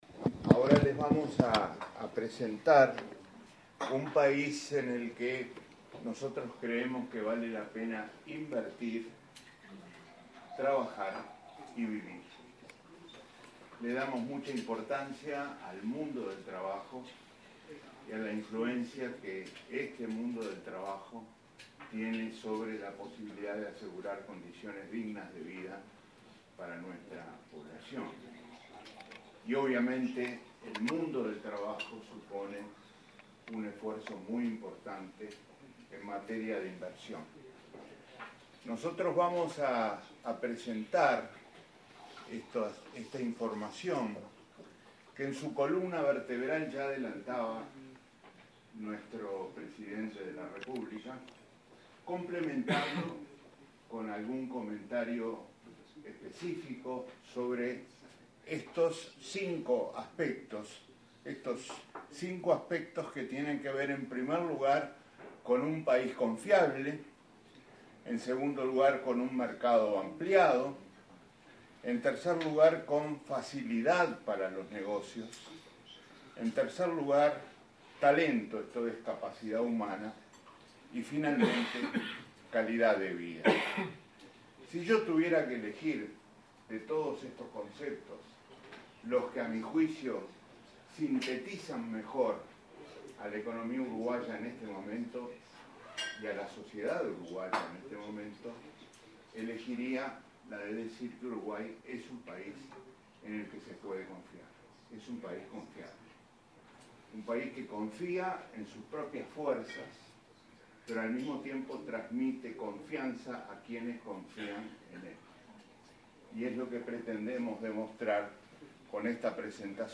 El ministro de Economía, Danilo Astori, expuso este jueves en un almuerzo con empresarios alemanes donde resaltó la importancia de la inversión
Reiteró la importancia de la reinversión y resaltó que entre 2005 y 2015, el 61% de las utilidades de inversiones extranjeras se reinvirtieron. A continuación, la exposición del ministro Astori.